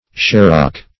Search Result for " sharock" : The Collaborative International Dictionary of English v.0.48: Sharock \Shar"ock\, n. An East Indian coin of the value of 121/2 pence sterling, or about 25 cents.